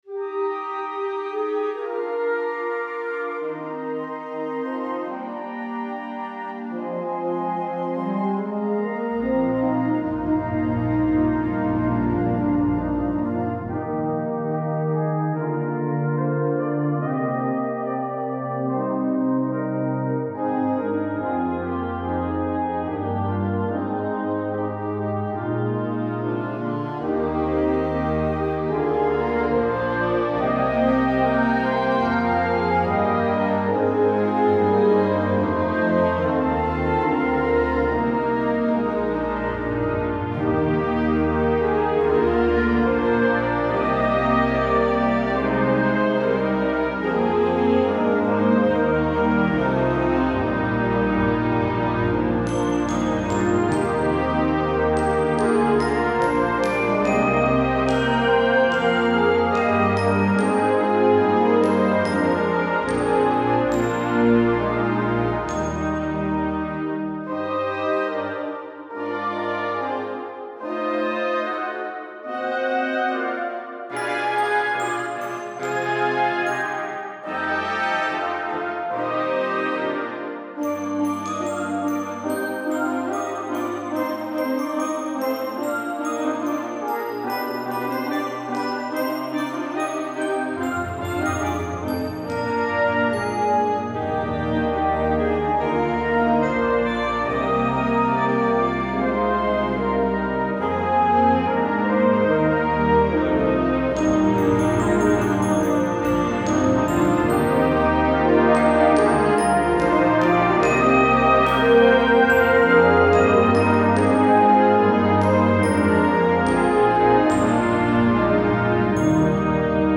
Gattung: Kirchenmusik, Choral
Besetzung: Blasorchester